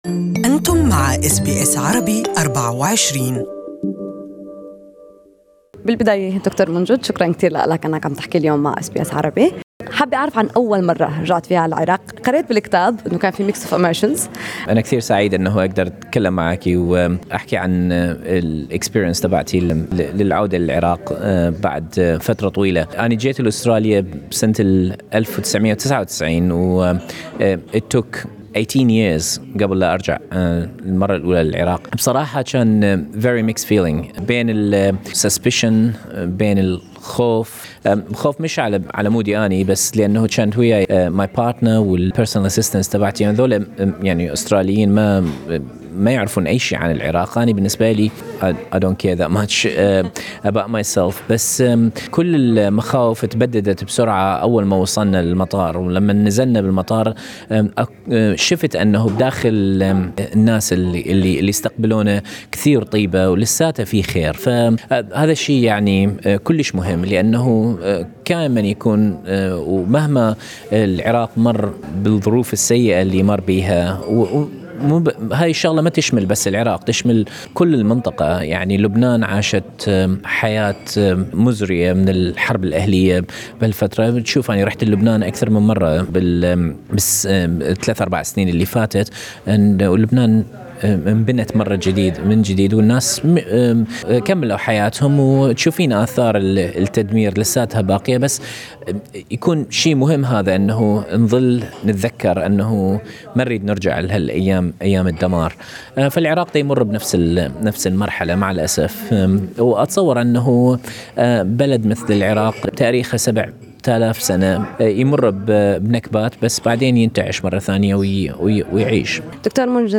نظمت شبكة التعددية الثقافية التابعة لبلدية Lower North Shore فعالية فطور بمناسبة اسبوع اللاجئين في أستراليا.